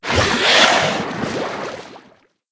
c_seasnake_atk1.wav